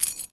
R - Foley 15.wav